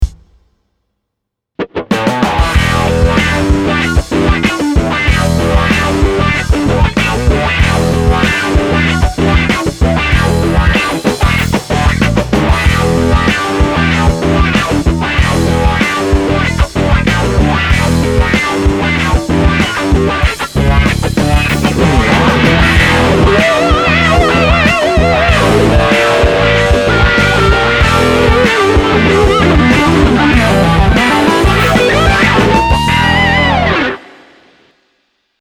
Gitarové Kombo Trubica Zosilnovača
Metropolitan20jimi20Style.mp3